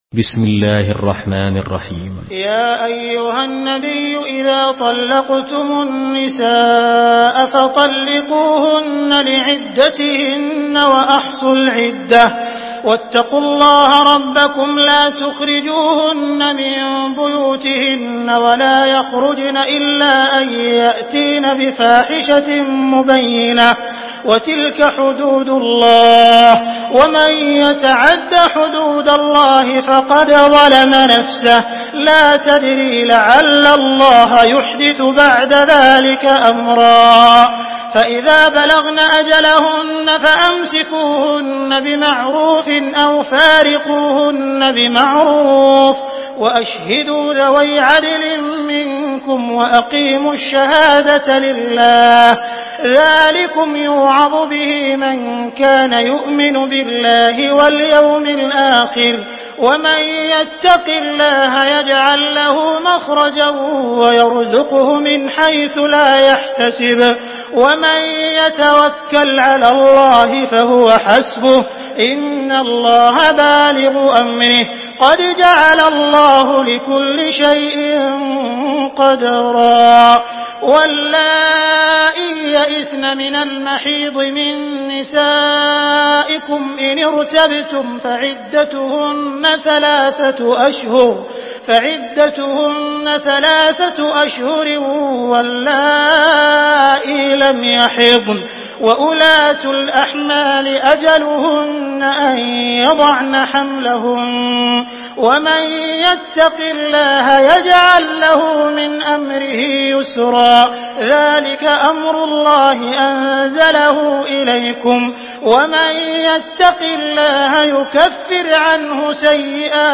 سورة الطلاق MP3 بصوت عبد الرحمن السديس برواية حفص
مرتل